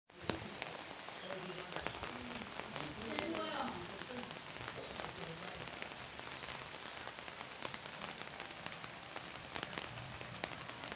声乐共鸣 " 声乐范围共鸣22mix
描述：立体声中音男低音共，我一次唱完整个音域，短促的爆发，混合22
标签： 中音 低音 票据 男高音 在一起 人声
声道立体声